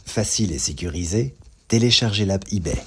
Bouclage 2